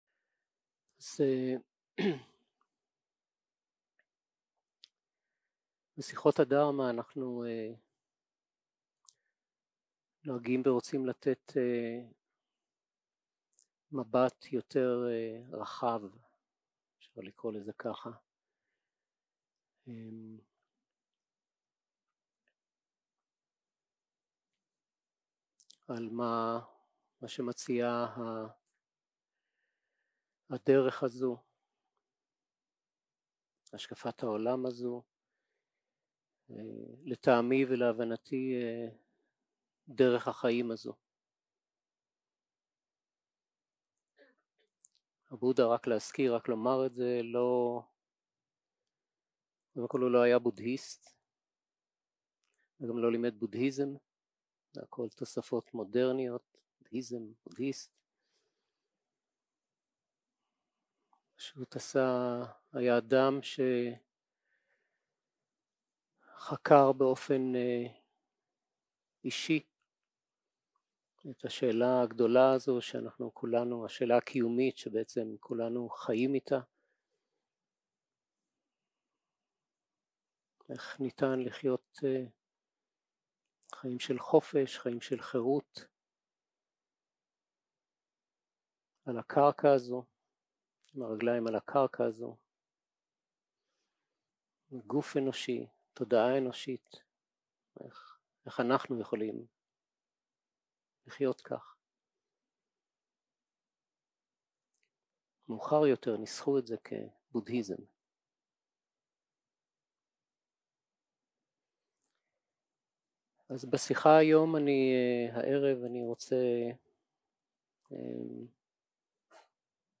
יום 2- הקלטה 3 - ערב - שיחת דהרמה - להיות עם טבע הדברים
סוג ההקלטה: שיחות דהרמה